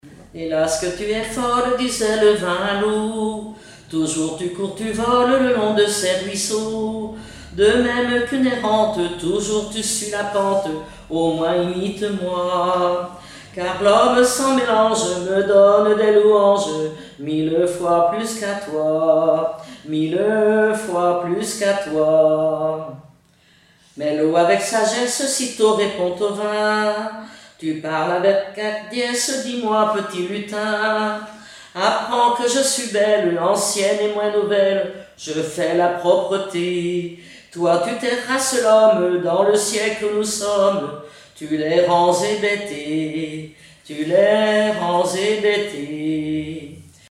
Genre dialogue
chansons et témoignages parlés
Pièce musicale inédite